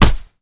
kick_soft_jab_impact_04.wav